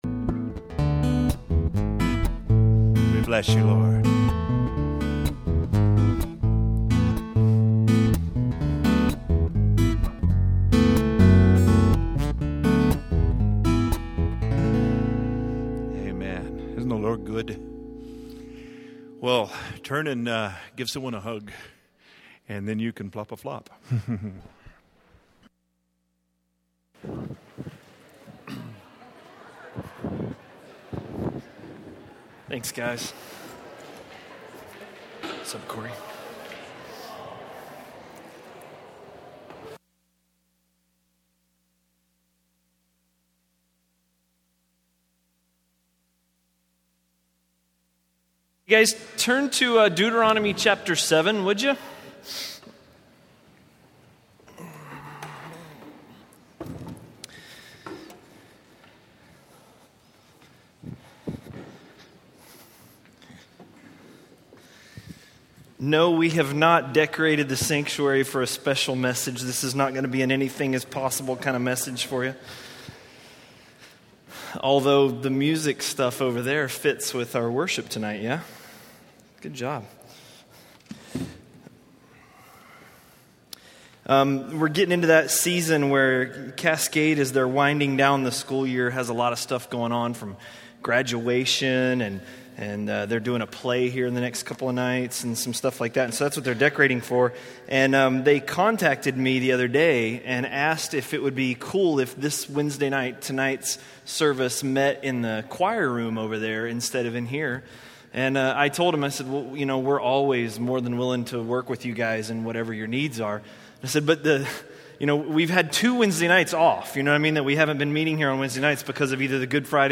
A message from the series "(Untitled Series)." by